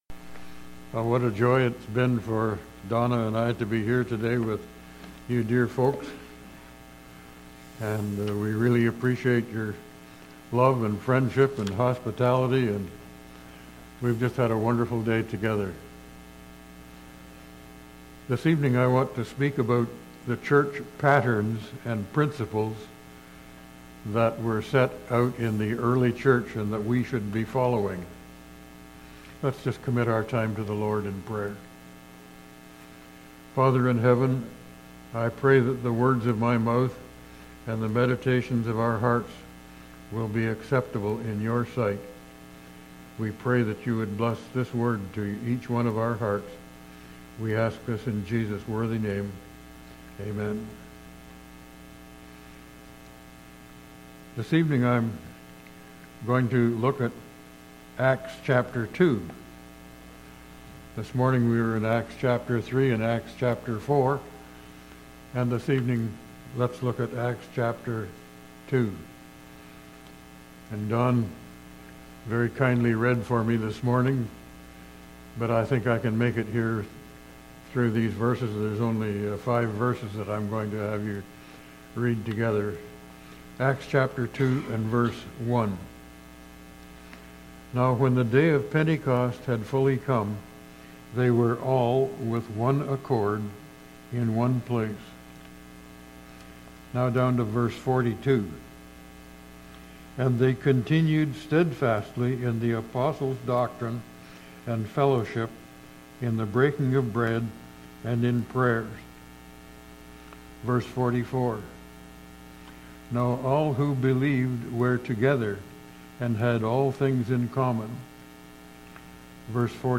Bible Text: Acts 2: 1,42,44,47 | Preacher